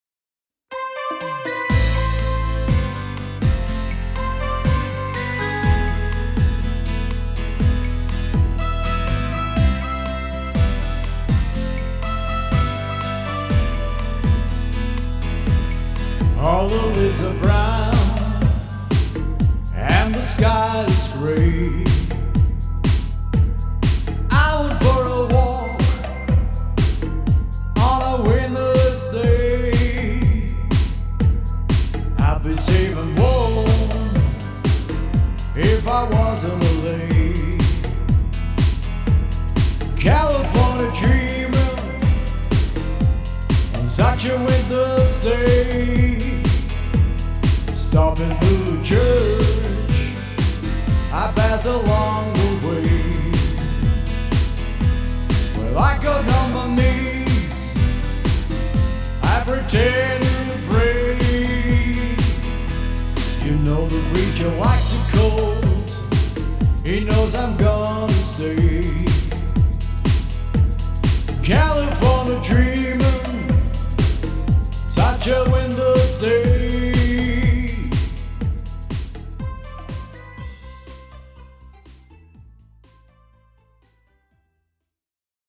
Pop & Rock & Swing